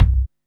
MOTOR.wav